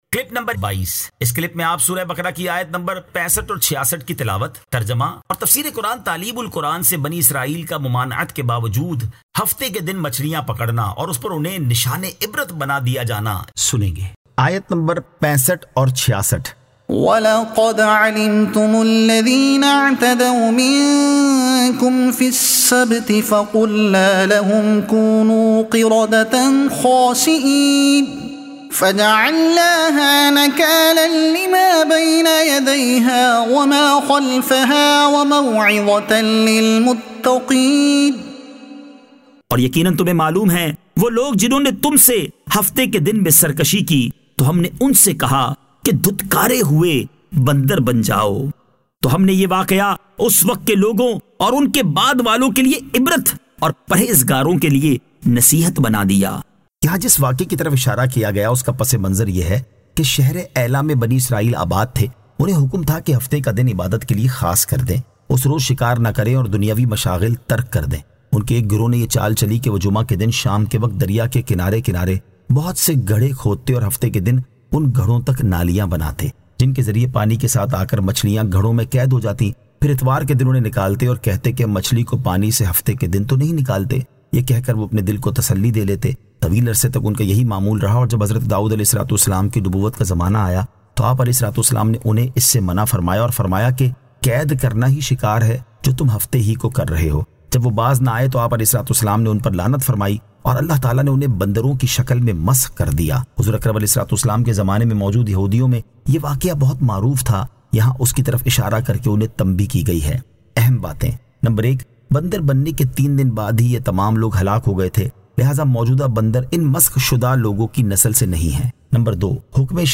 Surah Al-Baqara Ayat 65 To 66 Tilawat , Tarjuma , Tafseer e Taleem ul Quran